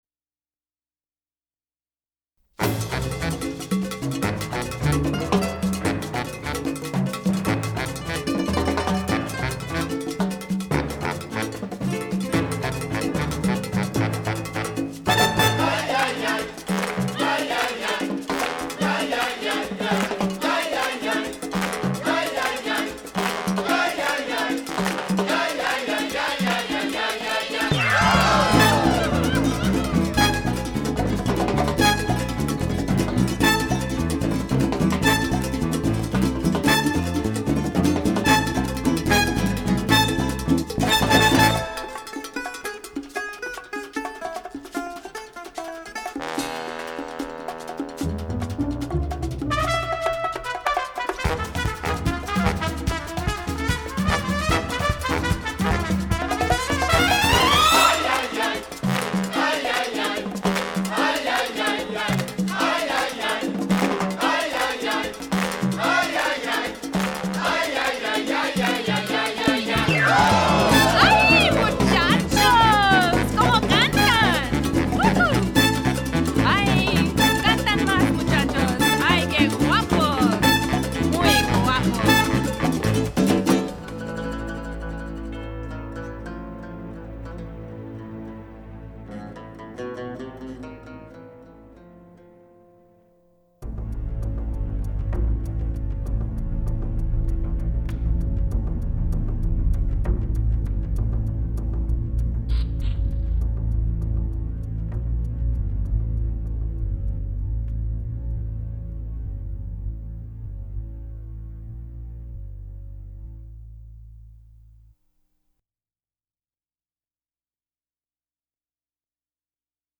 Background Coro Music
Chase Scene & Closing credits/Guest Vocals